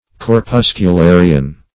Meaning of corpuscularian. corpuscularian synonyms, pronunciation, spelling and more from Free Dictionary.
corpuscularian.mp3